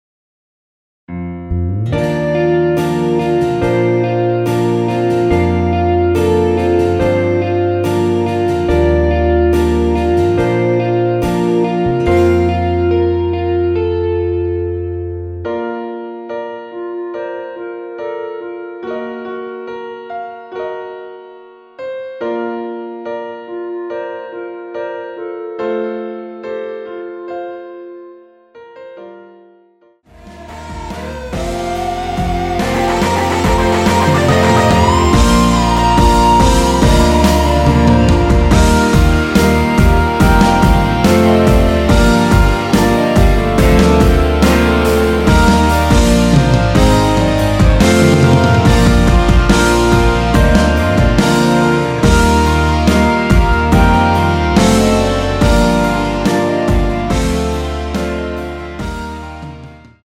원키에서(-1)내린 멜로디 포함된 MR입니다.
Bb
앞부분30초, 뒷부분30초씩 편집해서 올려 드리고 있습니다.
중간에 음이 끈어지고 다시 나오는 이유는